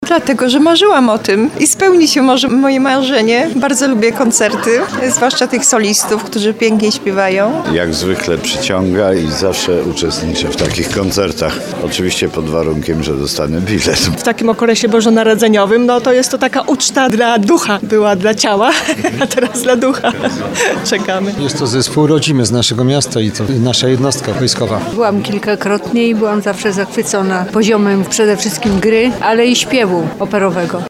2sonda_kolejka.mp3